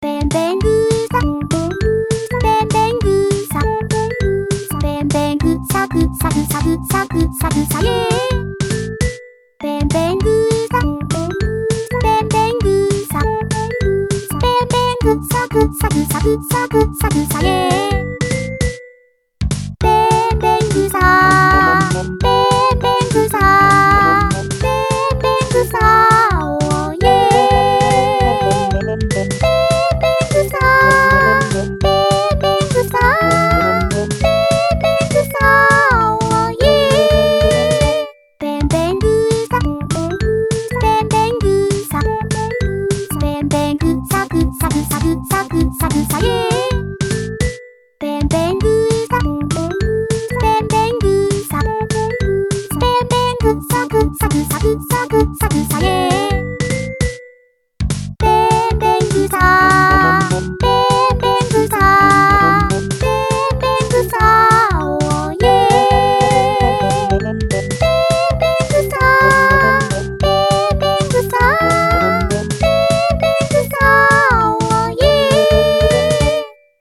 penpengusa_miku_ver2.mp3